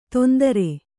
♪ tondare